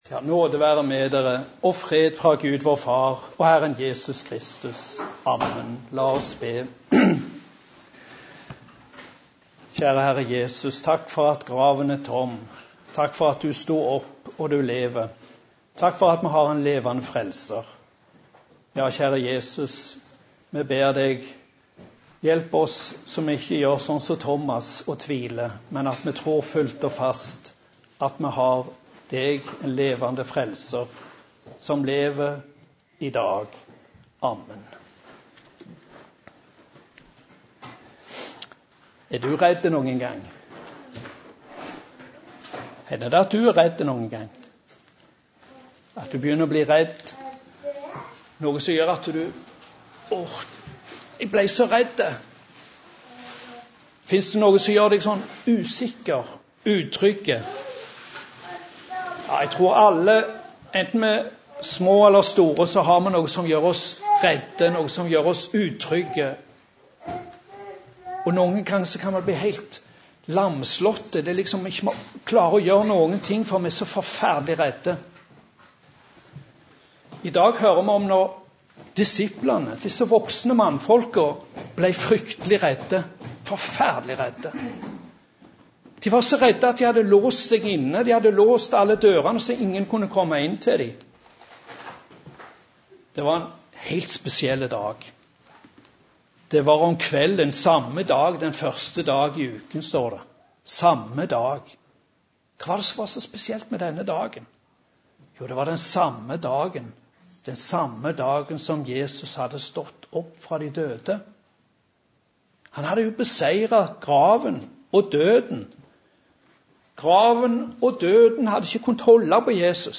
Preken på 1. søndag etter påske